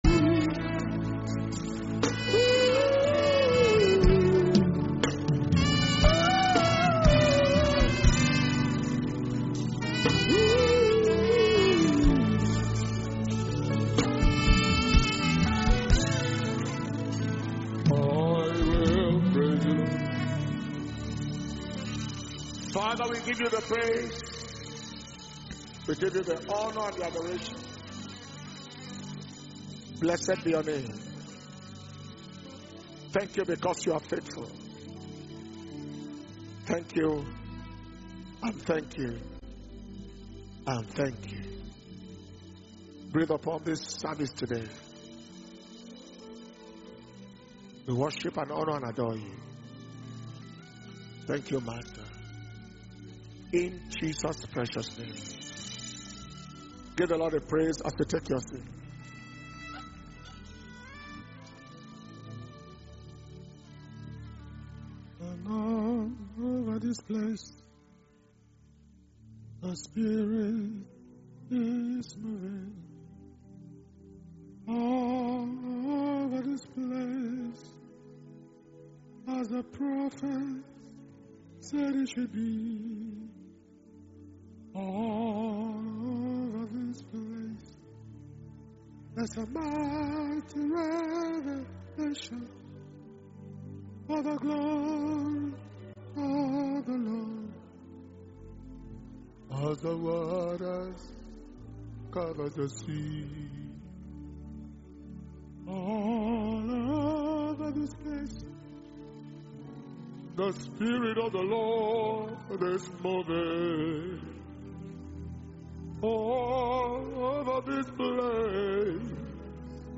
International Music Ministers’ Conference 2021– Day 2 - Thursday, 29th July 2021